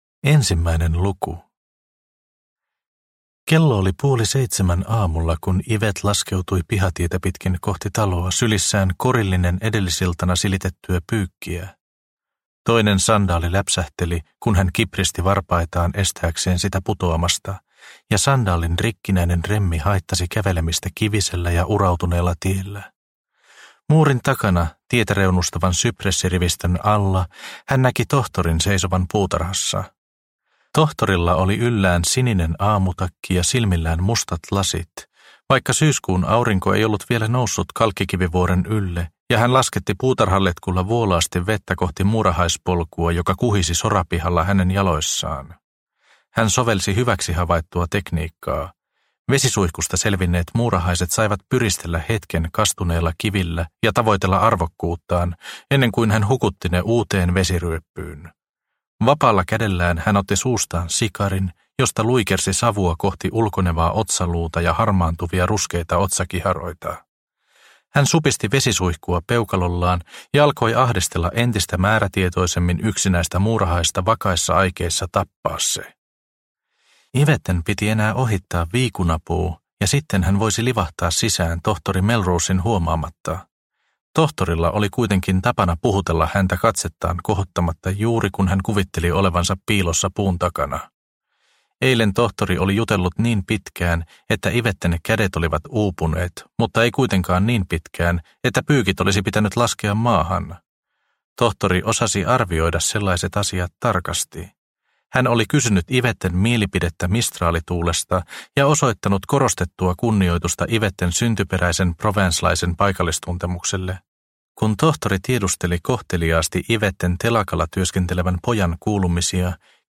Loistava menneisyys – Ljudbok – Laddas ner